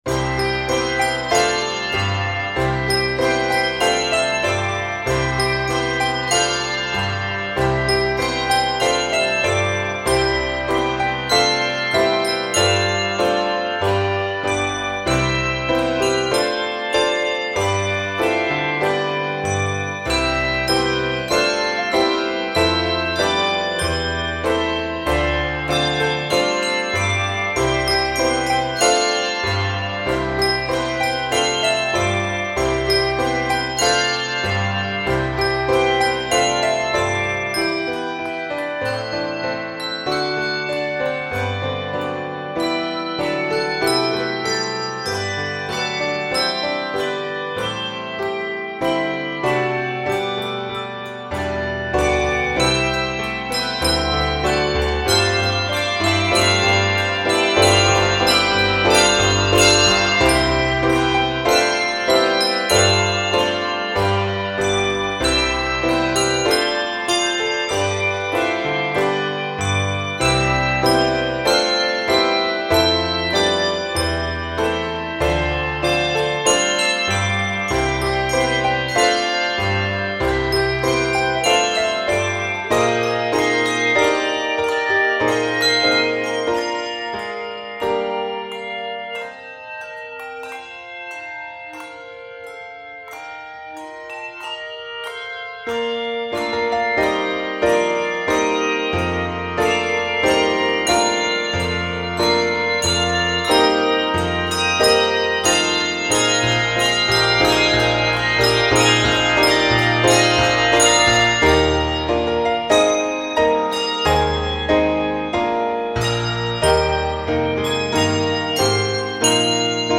Keys of C Major and Eb Major.